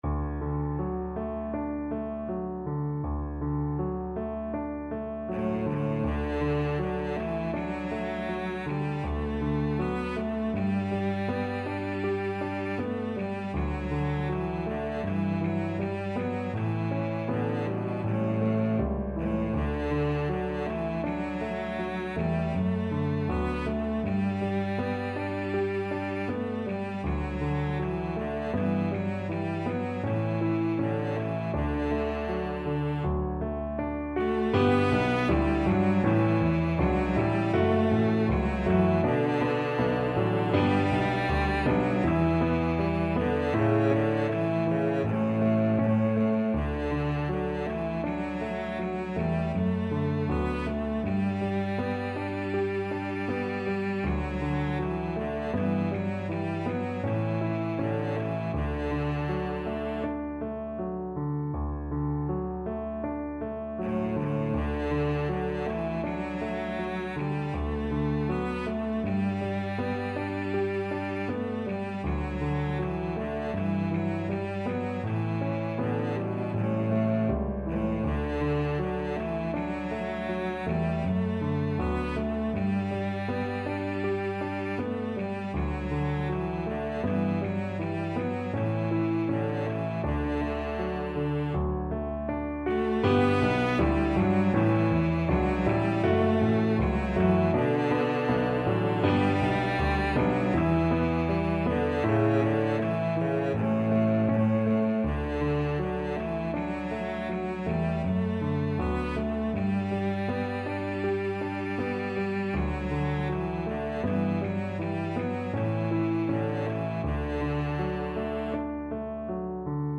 2/4 (View more 2/4 Music)
A3-B4
Andante